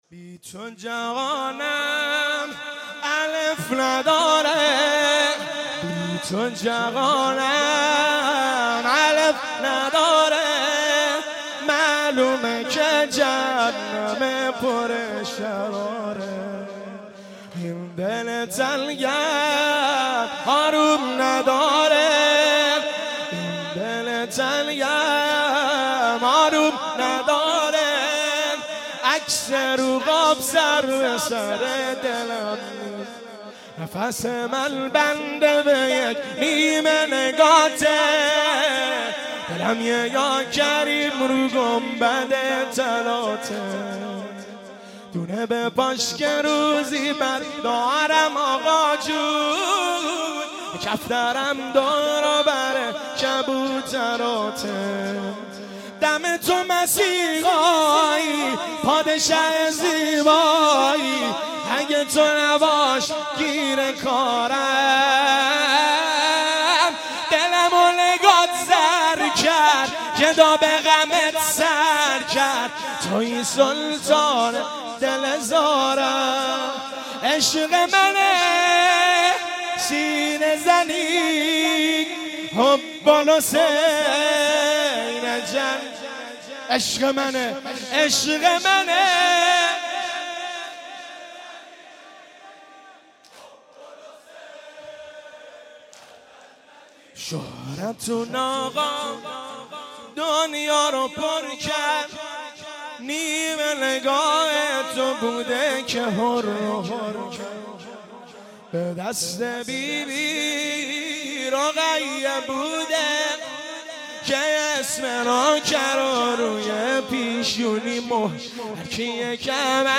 مناسبت : وفات حضرت زینب سلام‌الله‌علیها